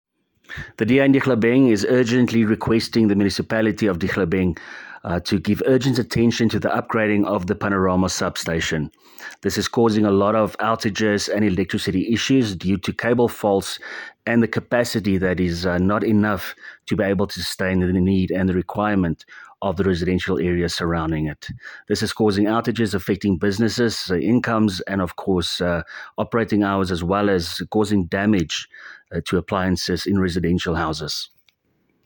Issued by Willie Theunissen – DA Councillor Dihlabeng Local Municipality
Afrikaans soundbites by Cllr Willie Theunissen and